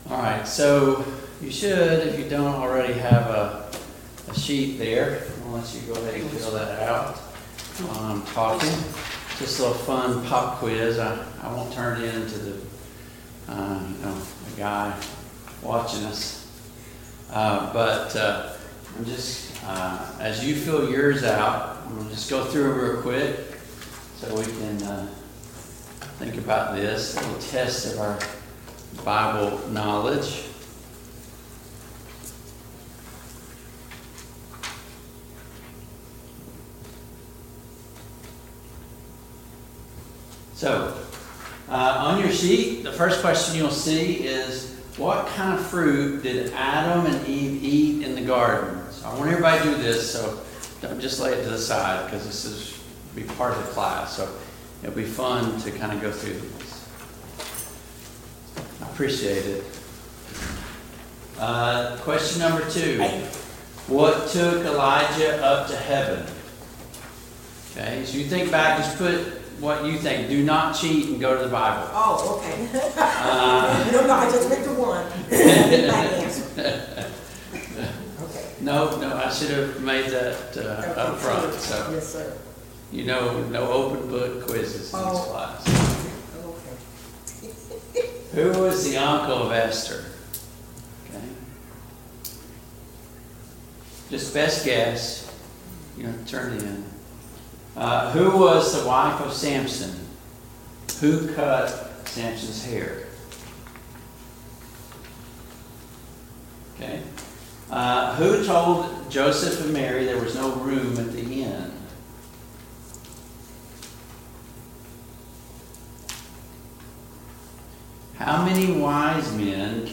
Sunday Morning Bible Class